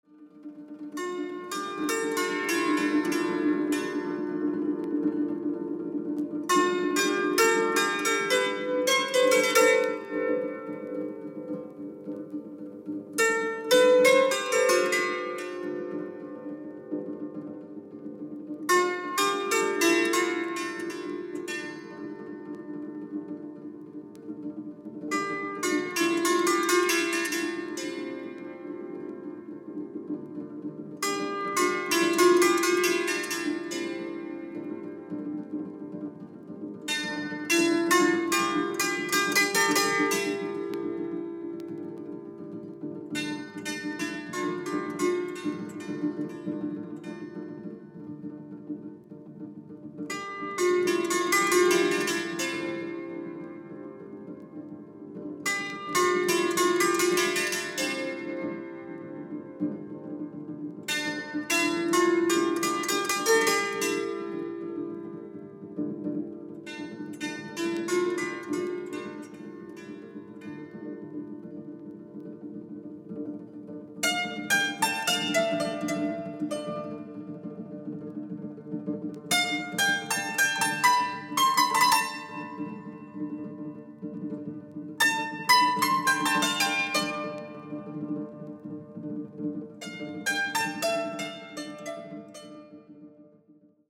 Artist : Les Musiciens De Provence